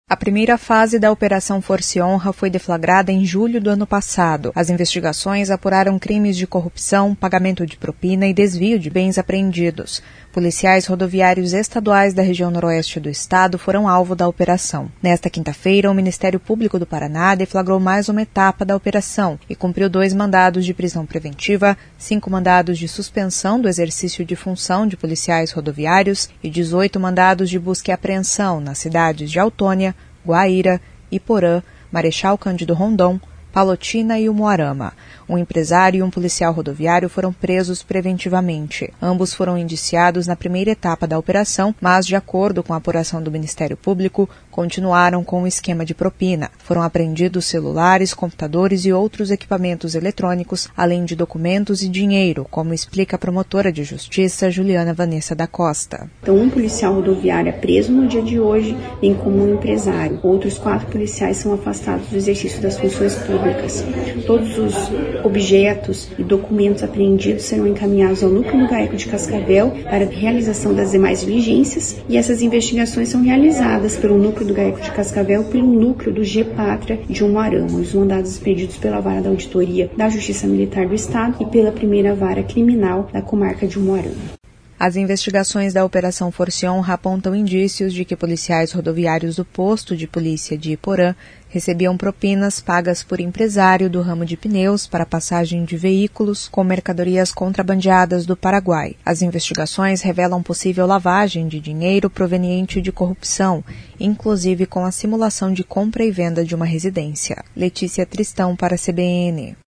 Foram apreendidos celulares, computadores e outros equipamentos eletrônicos, além de documentos e dinheiro, como explica a promotora de Justiça, Juliana Vanessa da Costa.